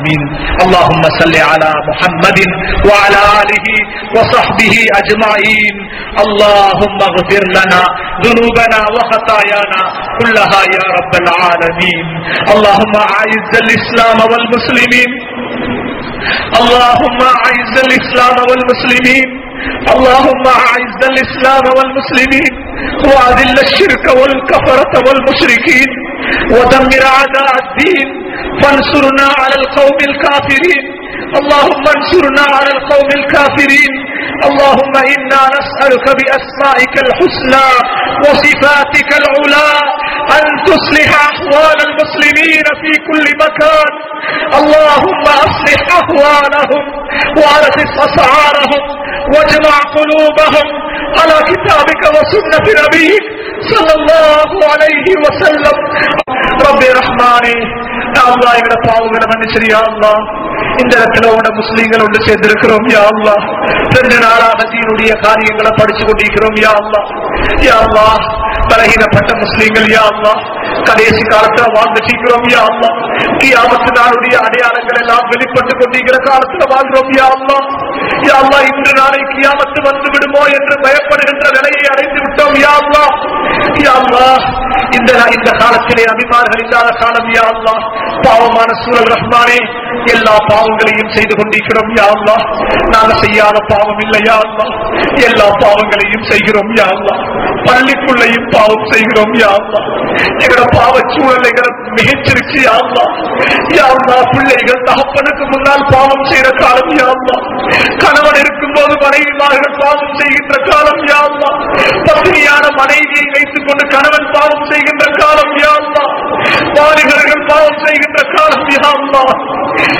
SPECIAL DUA for GAZA | Audio Bayans | All Ceylon Muslim Youth Community | Addalaichenai